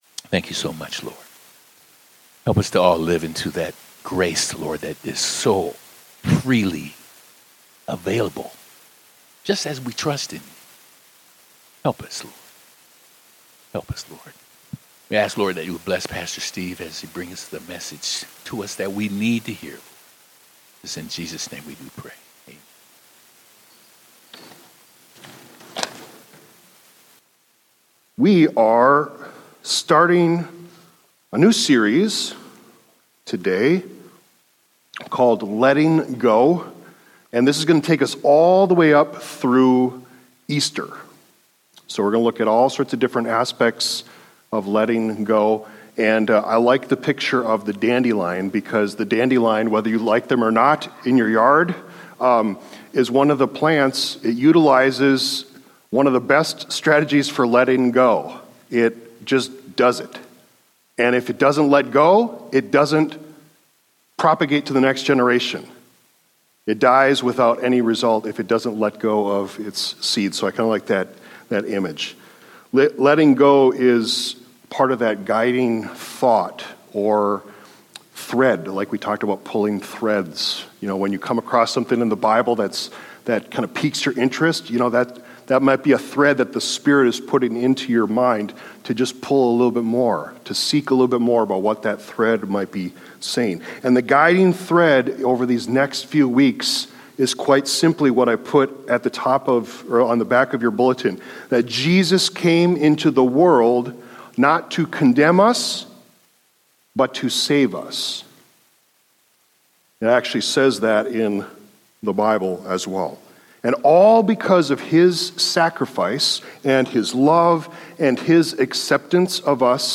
sermons – Page 3 – Edgewater Covenant Church